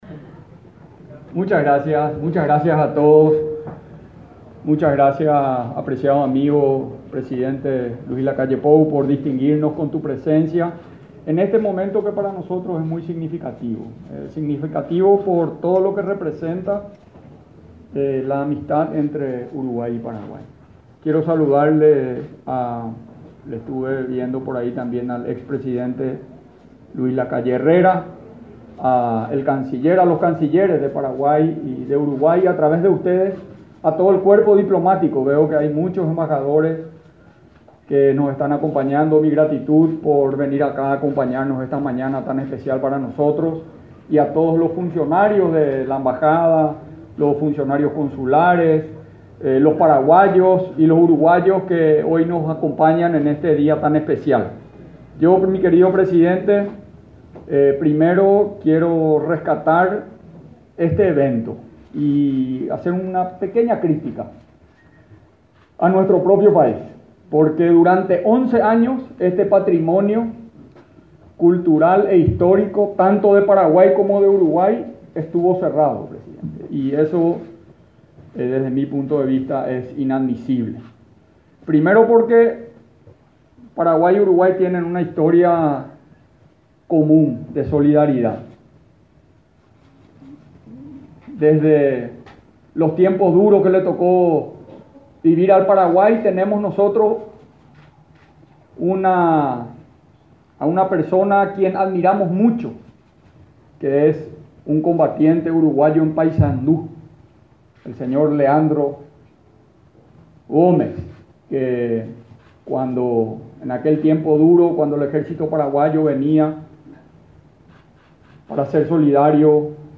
Palabras del presidente paraguayo, Mario Abdo Benítez
El presidente de Paraguay, Mario Abdo Benítez, participó en la inauguración de obras en la Embajada de de ese país en Montevideo.